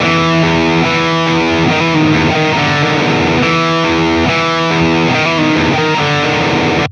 mal ein Gedanke zum Thema; Bratgitarren und Ampsimulation
Ich hab es mal "ge-reamped".